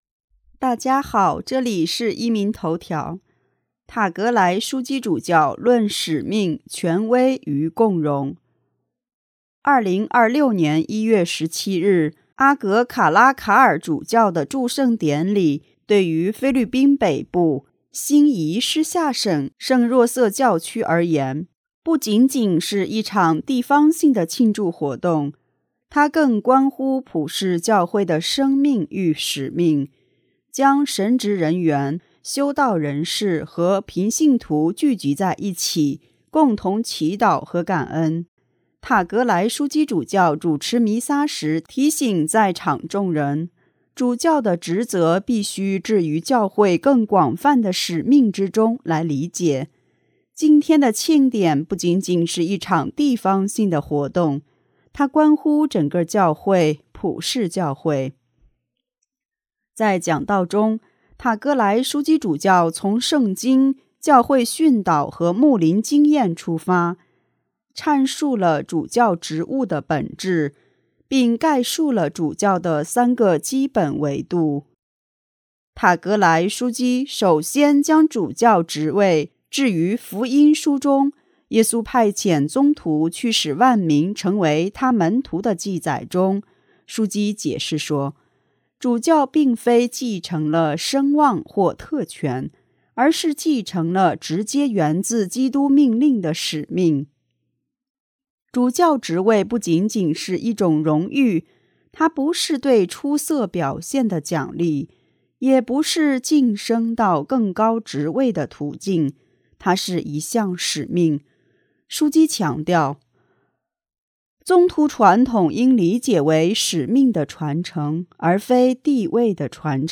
——本台报道